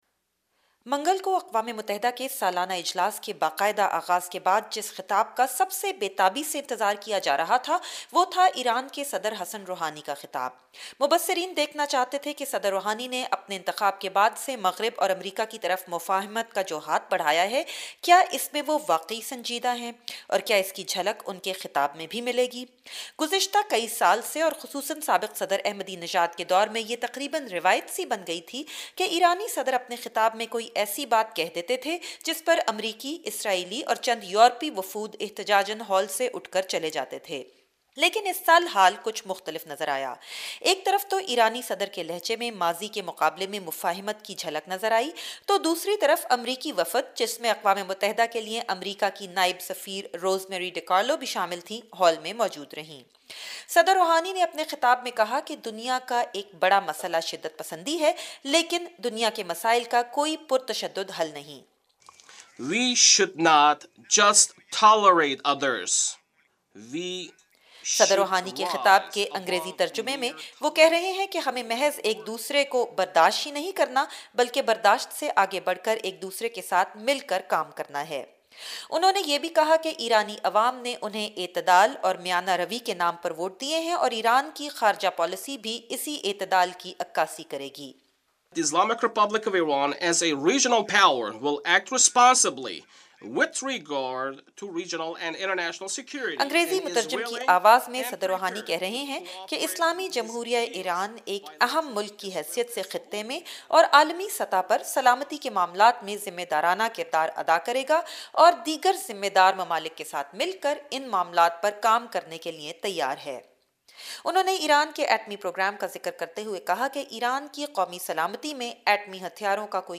ریڈیو رپورٹ